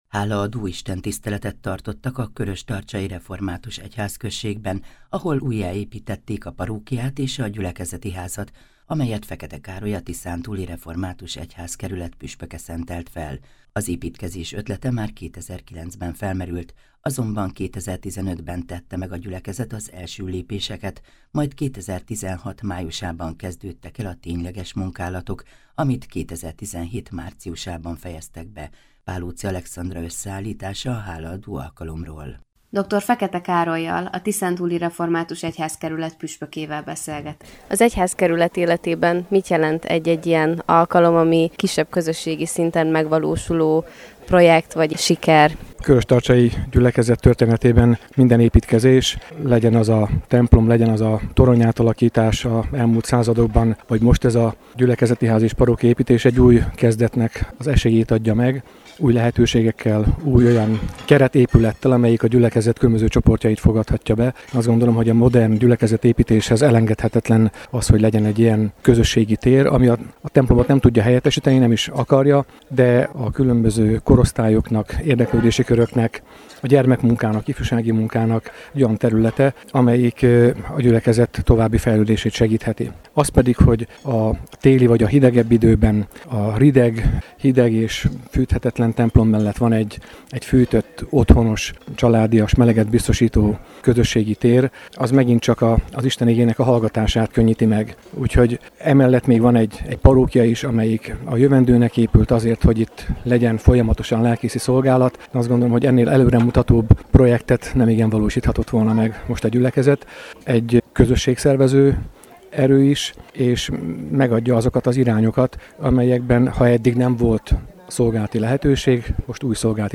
Hálaadó istentisztelet a köröstarcsai református templomban - hanganyaggal